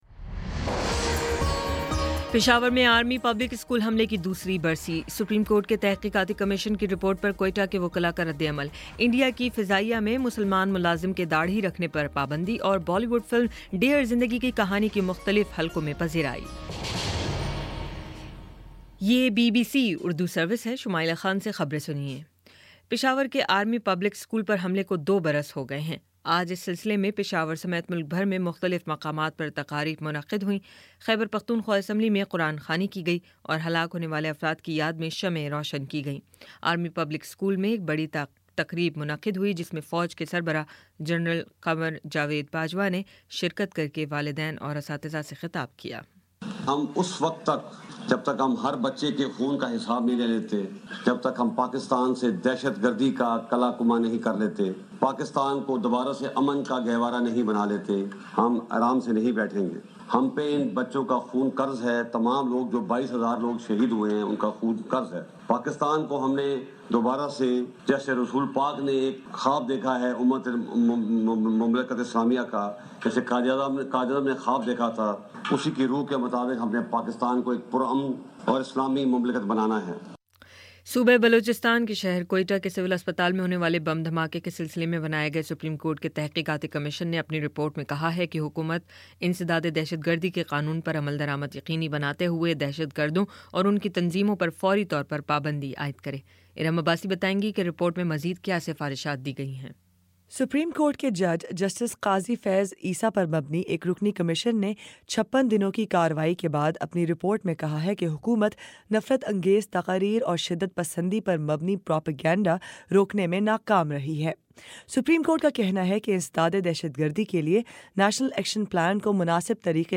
دسمبر 16 : شام چھ بجے کا نیوز بُلیٹن